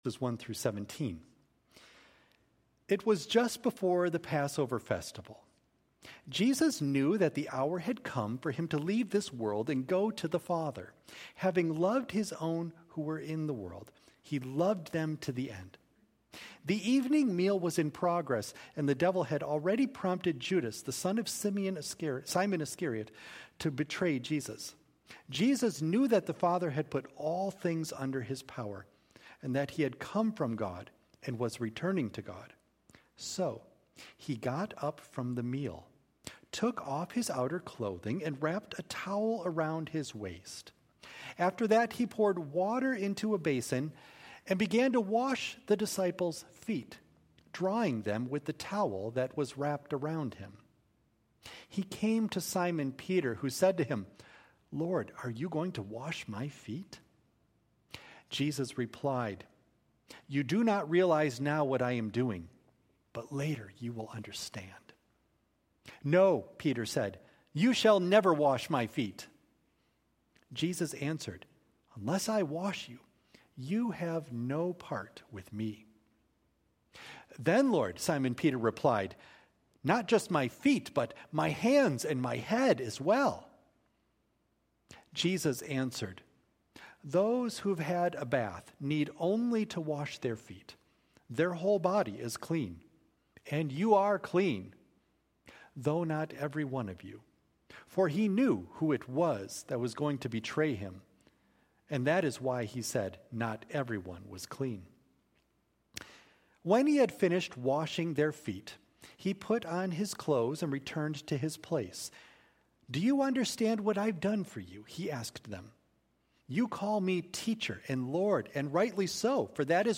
A service of reflection and communion for Maundy Thursday